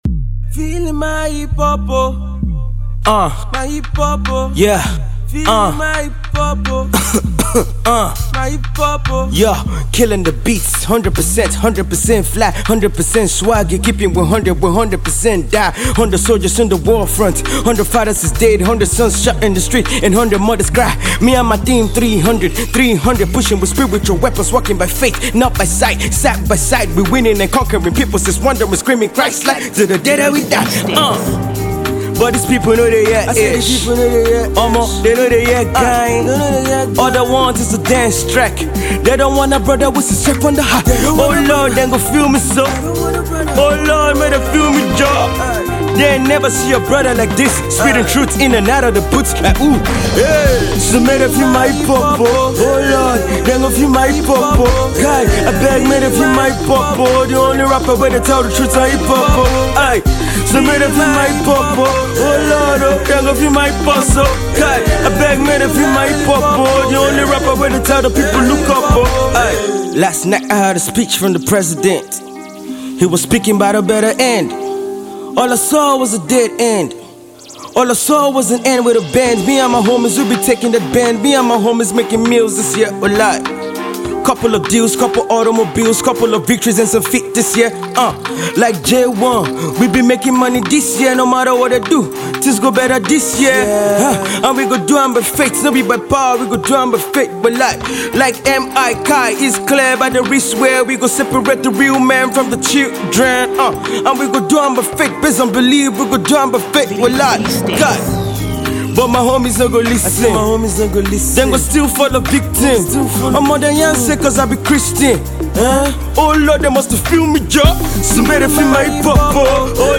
Fast rising Nigerian Hip Hop artiste
The South-South Nigeria based rapper said the song: